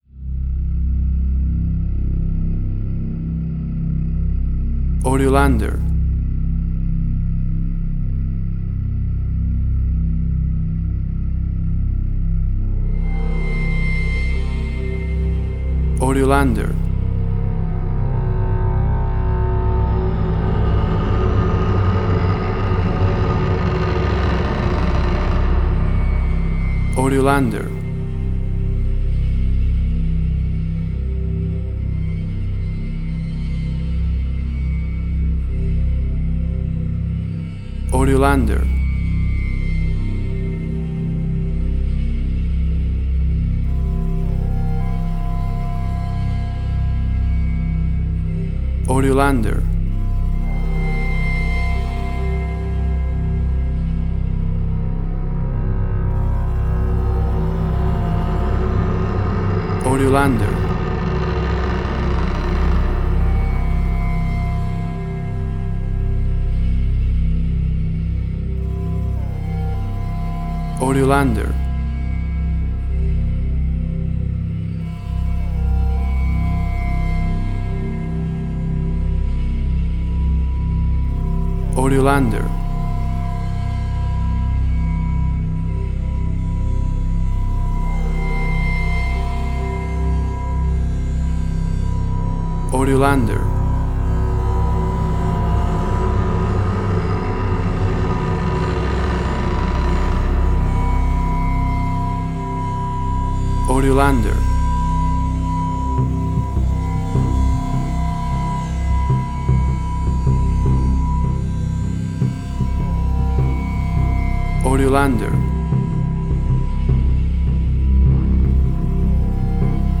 Suspense, Drama, Quirky, Emotional.